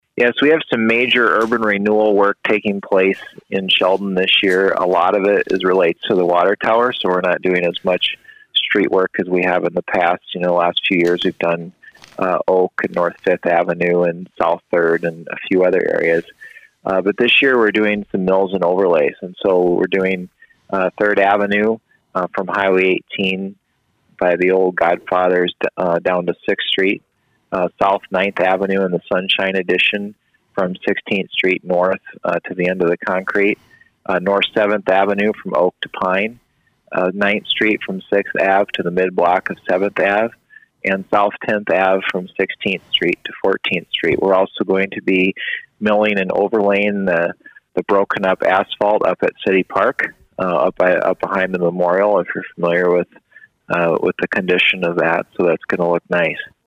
Kooiker addressed the issue.